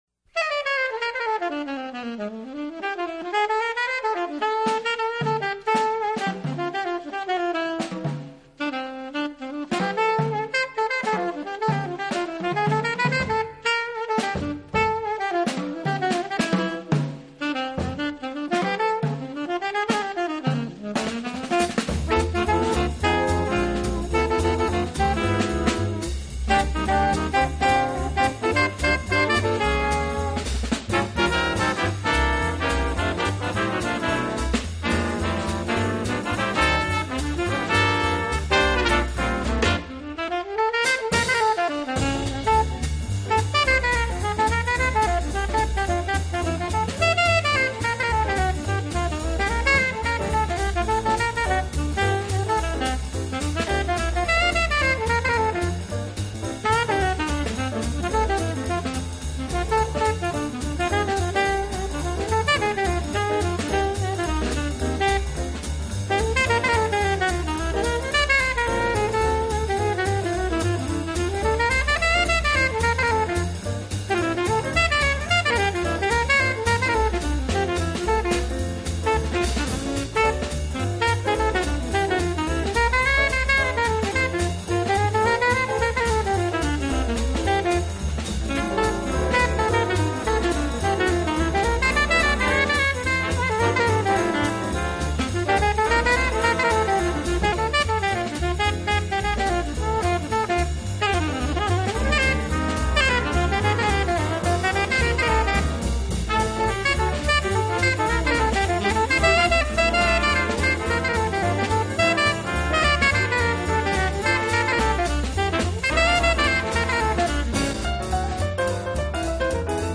音乐类型：Jazz